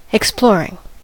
exploring: Wikimedia Commons US English Pronunciations
En-us-exploring.WAV